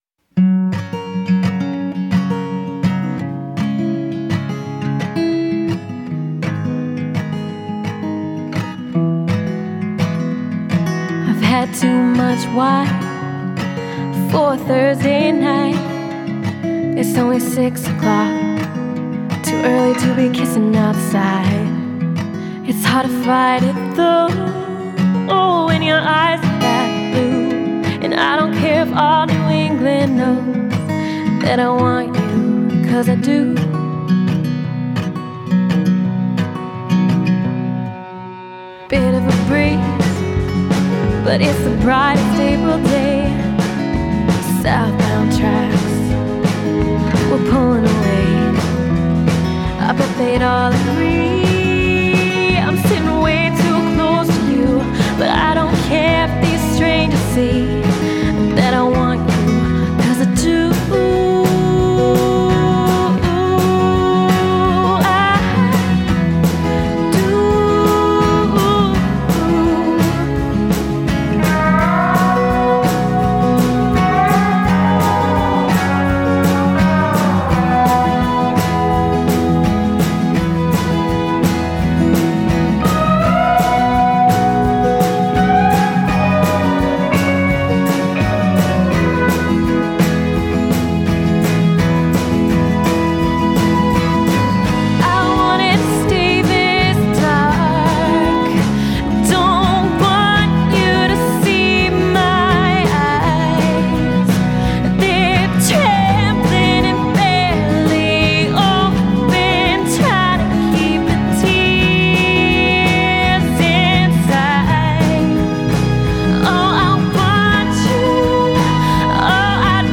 • Genre: Folk / Pop
Akustische Gitarre, E-Gitarre sowie der Bass